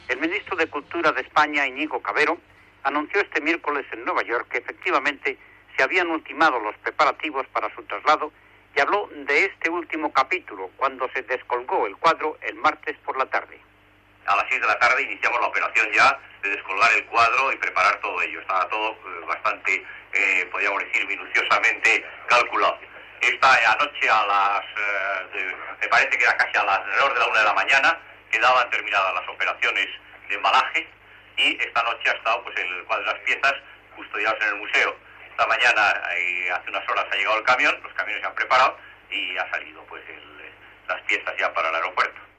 Informació de l'embalatge del quatre Guernica de Pablo Picasso al Museu d'Art Modern de Nova York. Declaració del Ministre de Cultura espanyol, Iñigo Cavero.
Informatiu